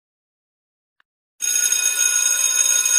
Train Whistle
Train Whistle is a free sfx sound effect available for download in MP3 format.
358_train_whistle.mp3